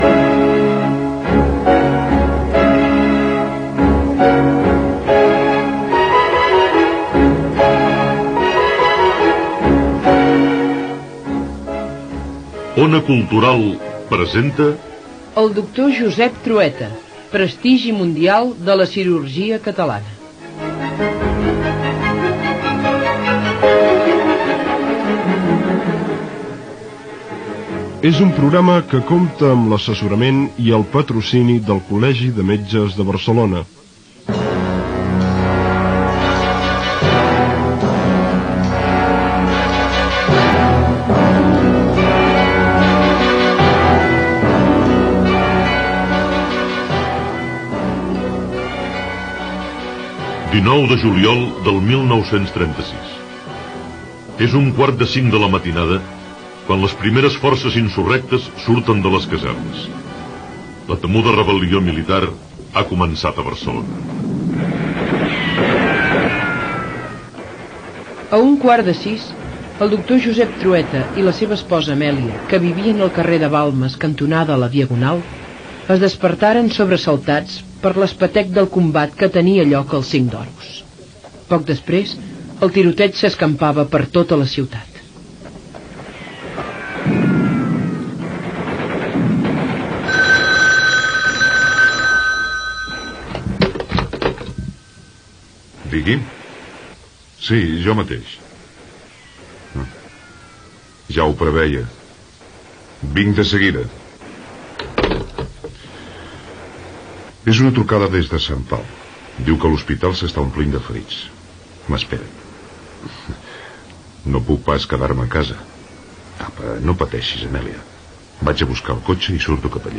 Espai dedicat a la biografia del doctor Josep Trueta i indicatiu d'Ona Cultural Gènere radiofònic Divulgació
Programa produït per Ona Cultural i emès per 15 emissores catalanes.